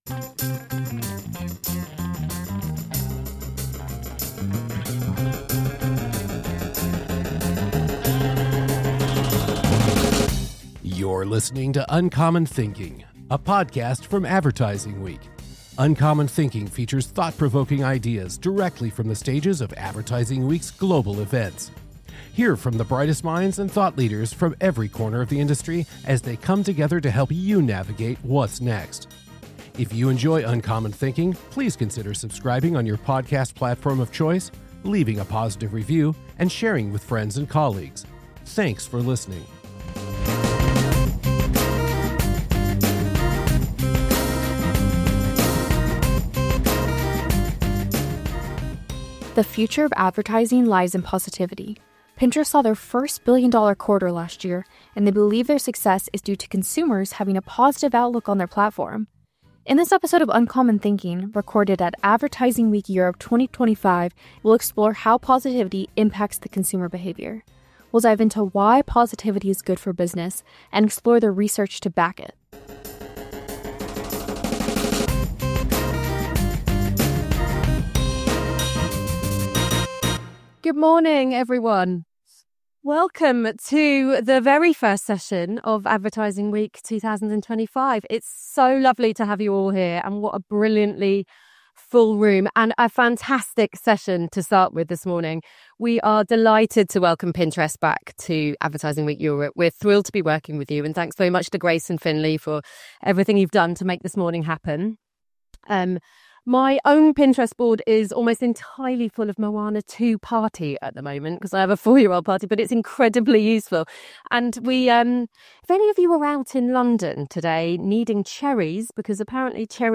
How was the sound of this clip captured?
Disruption, reinvention, pushing the boundaries these are the currency of advertising, marketing and modern business, and traits possessed by guests interviewed on the Advertising Week stage.